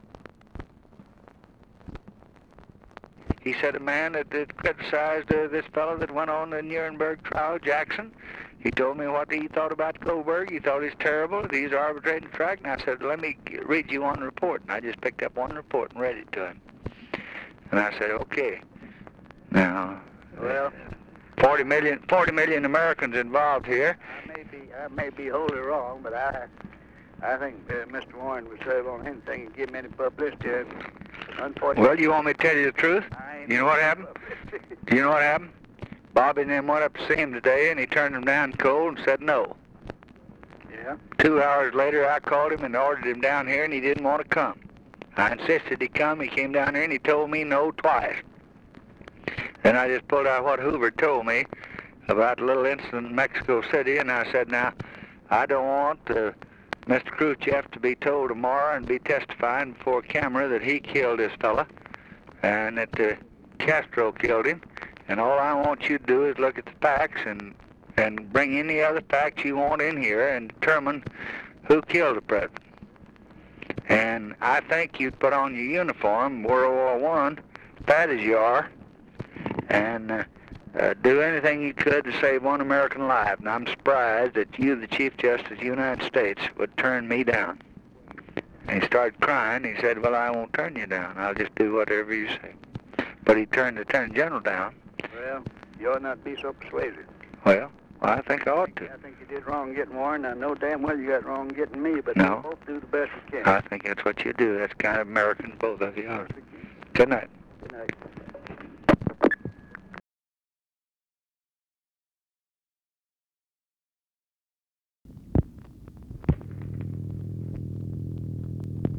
Conversation with RICHARD RUSSELL, November 30, 1963
Secret White House Tapes | Lyndon B. Johnson Presidency Conversation with RICHARD RUSSELL, November 30, 1963 Rewind 10 seconds Play/Pause Fast-forward 10 seconds 0:00 Download audio Previous Conversation with WILLIAM MCC.